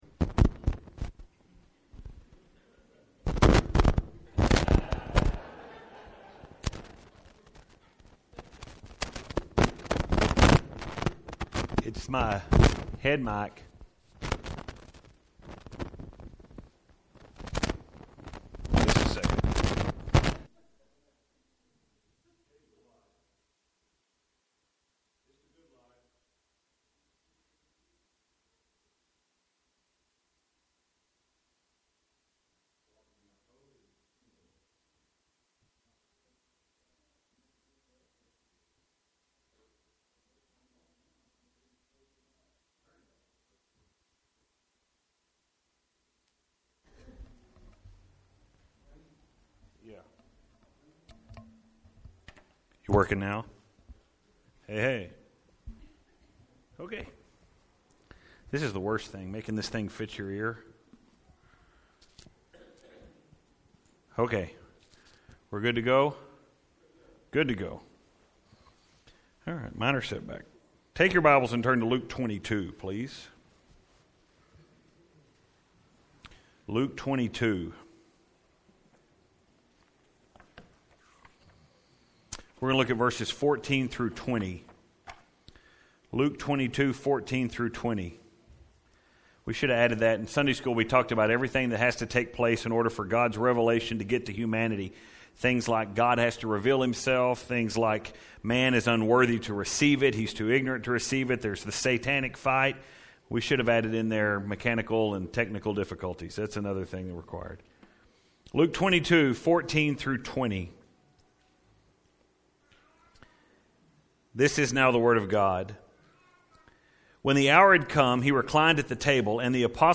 (Microphone difficulties, message begins at around the 1:00 minute mark)